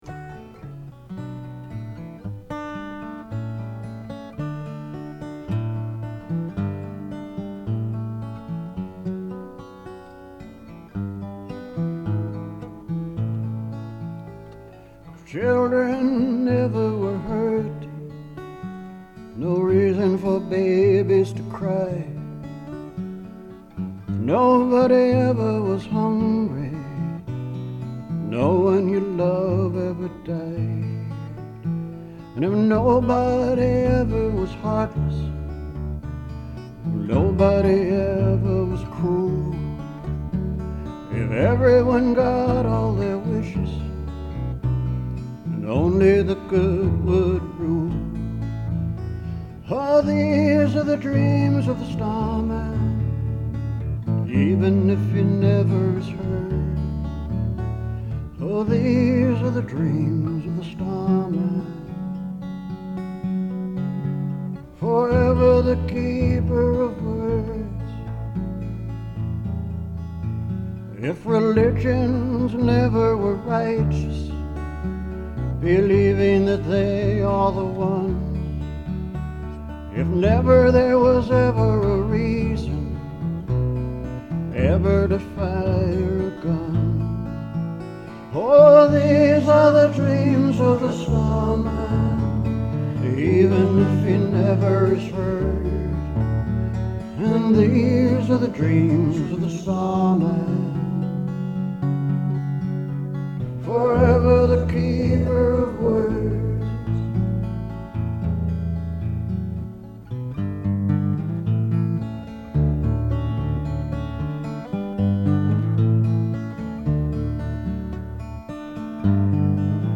World weary and wise.